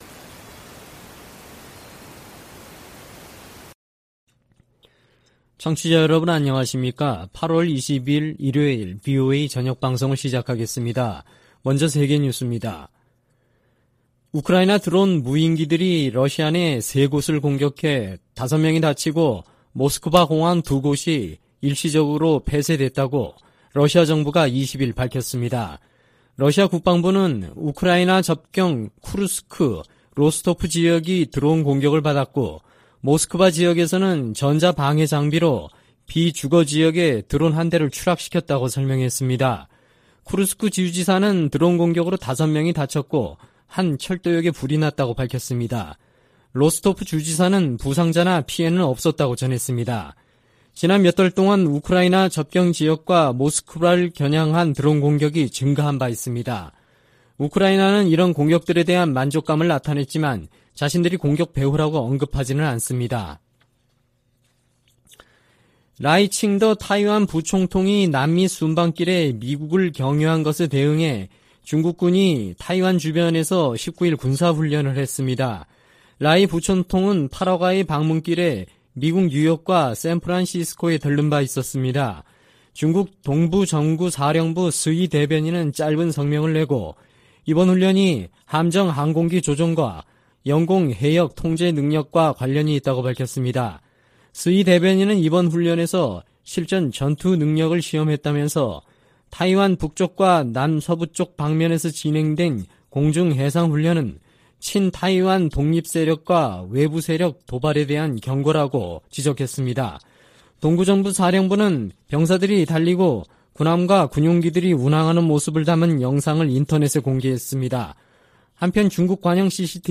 VOA 한국어 방송의 일요일 오후 프로그램 1부입니다. 한반도 시간 오후 8:00 부터 9:00 까지 방송됩니다.